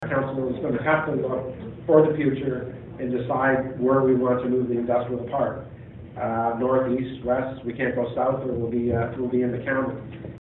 Ellis was speaking to the Belleville Chamber of Commerce’s annual Mayor’s Breakfast at The Grand Banquet Hall Wednesday.